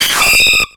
Cri de Mimigal dans Pokémon X et Y.